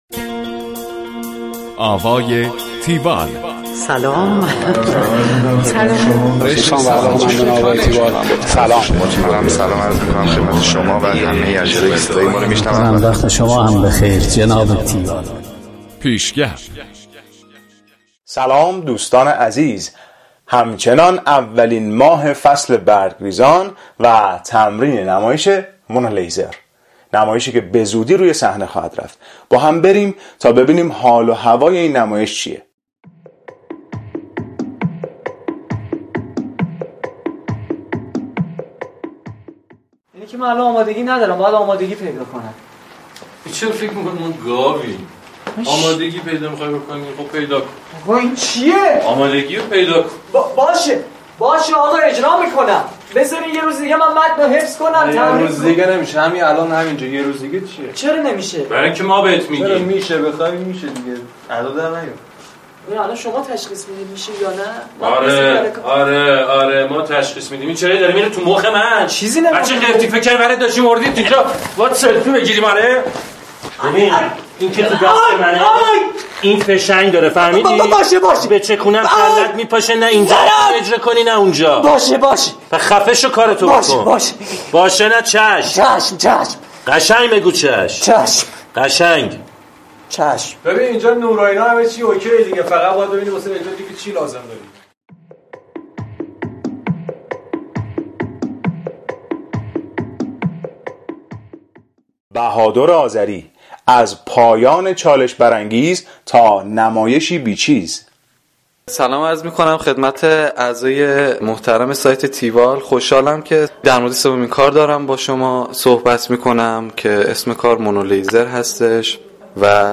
گزارش آوای تیوال از نمایش مونولیزر